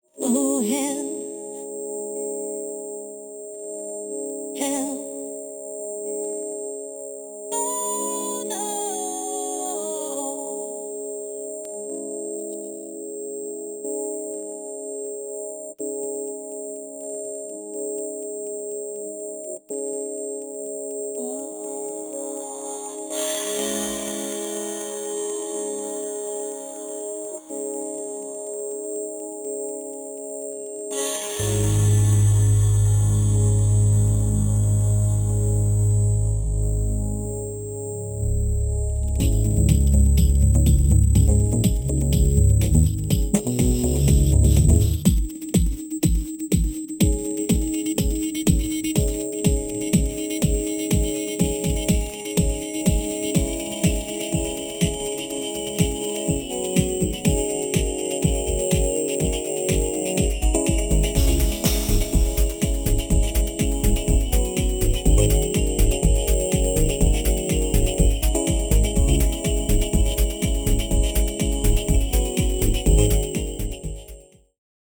Topic: Sudden high pitch distorted sound: Why is my new interface doing this?
Here's a recording of what the glitch sounds like, when it happens on my system:
MotuGlitch1.wav